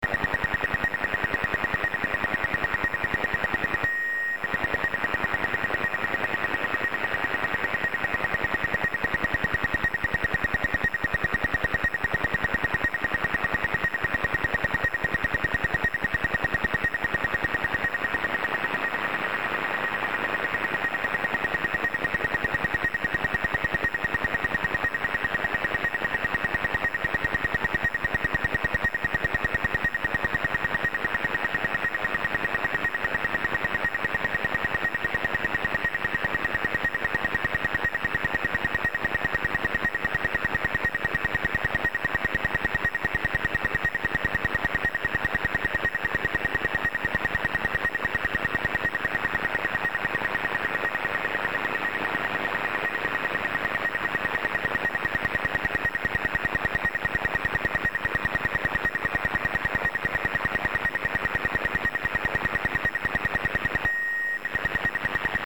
DXXXW signals.